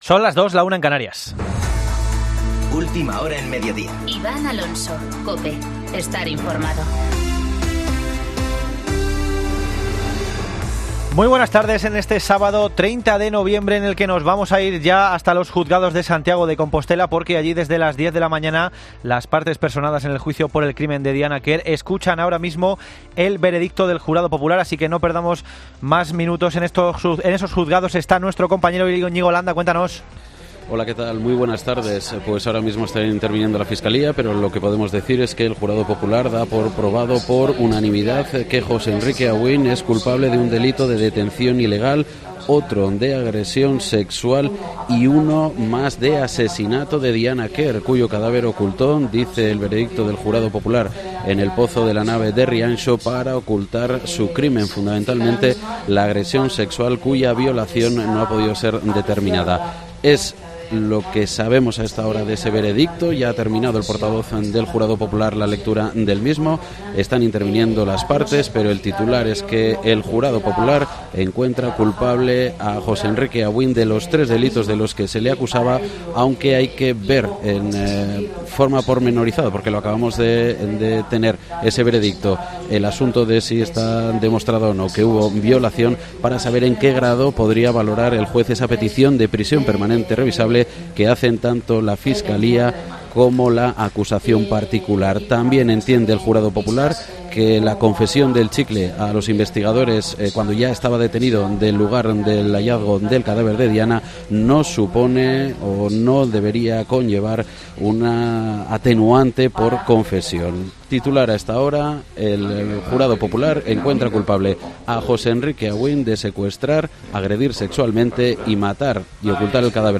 Boletín de noticias COPE del 30 de noviembre de 2019 a las 14.00 horas